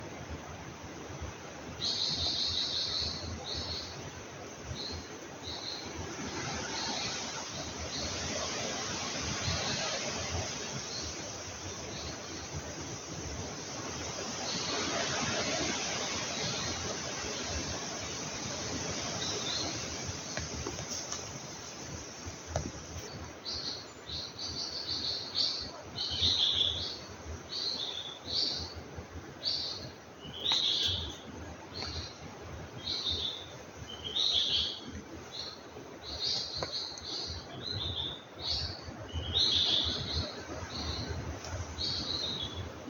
Australian Logrunner (Orthonyx temminckii)
Location or protected area: Lamington National Park
Condition: Wild
Certainty: Recorded vocal
australian-logrunner.mp3